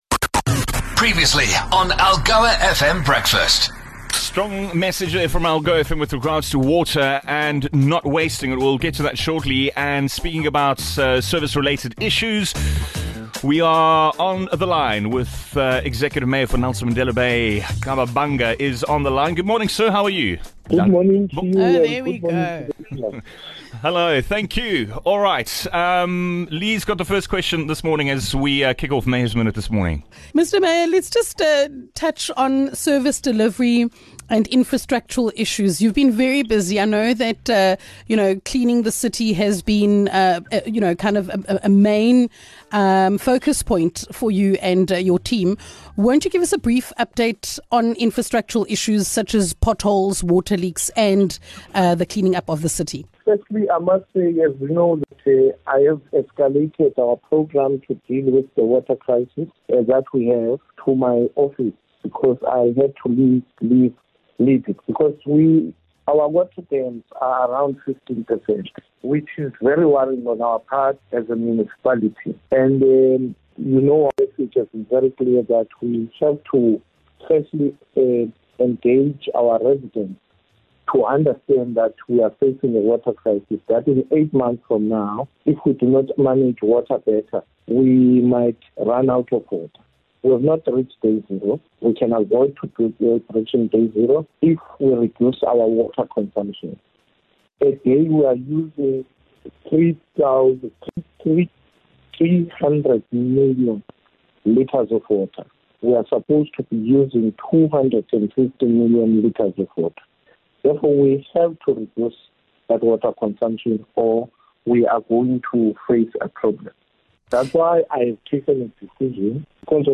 Executive Mayor Nqaba Bhanga reports on progress made - from saving water to pothole repair and stopping cable theft - he is proving to be a mover and a shaker.